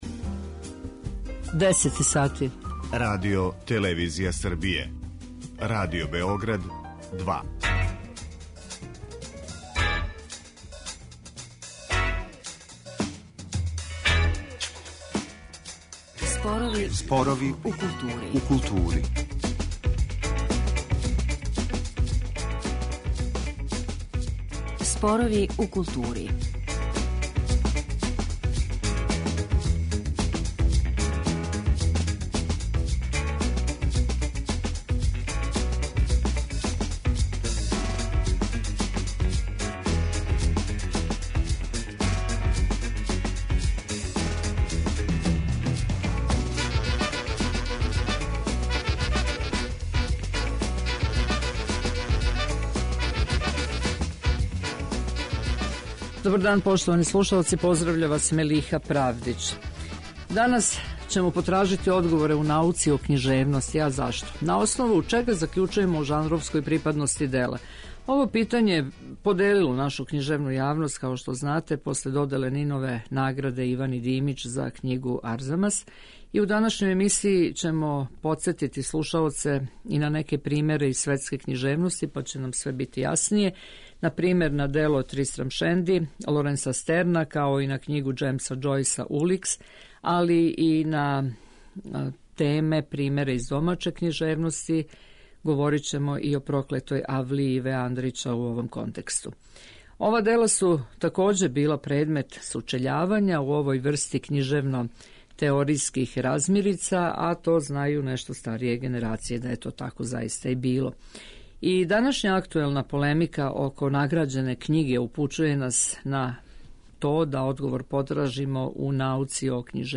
тонски забележен разговор о овој теми